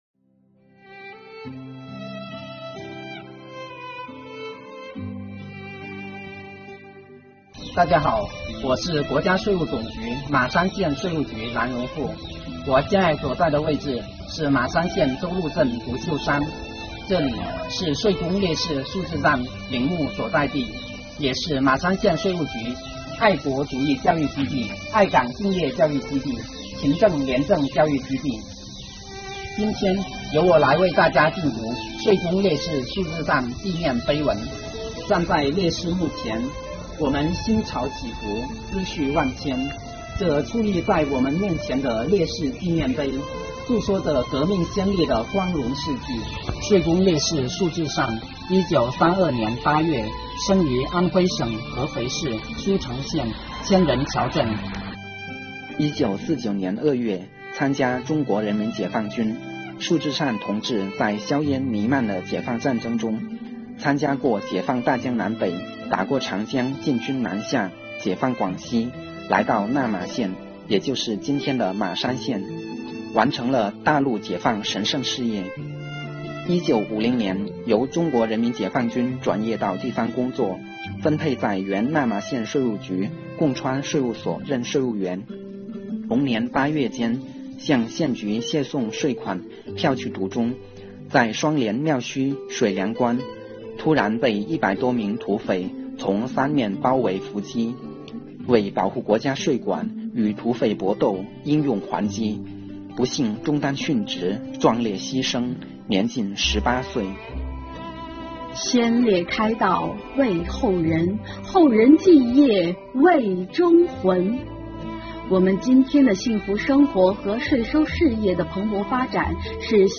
南宁税务青年理论学习小组成员到马山县周鹿镇的税工烈士束志善墓前缅怀先烈，号召大家一起：
扫墓除草，敬读碑文，